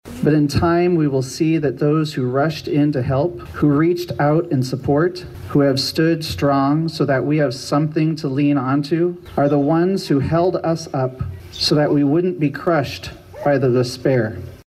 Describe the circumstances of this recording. The vigil was in front of the Adair County Courthouse on Tuesday night.